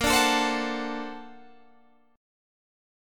Bb+M9 chord